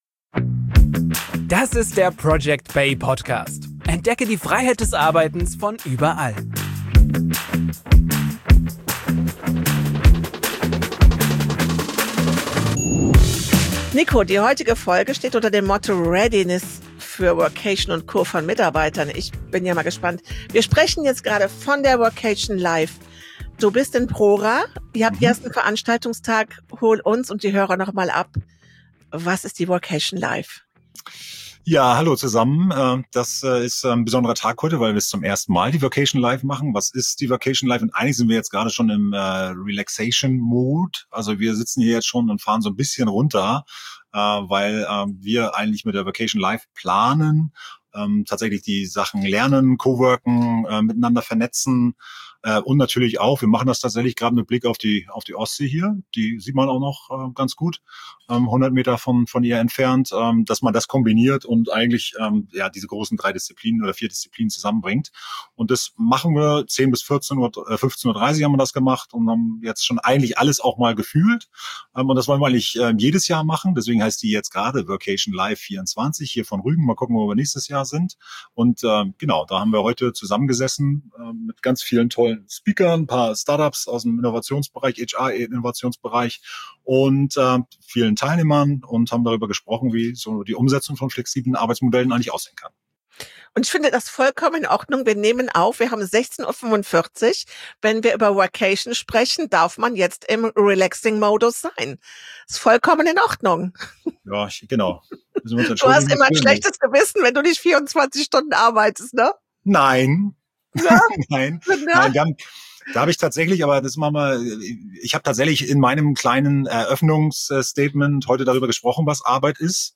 direkt von der Workation live 24 auf Rügen auf. An Tag 1 ging es dort vor allem um die Mitarbeiterbindung durch flexible Arbeitsumgebung.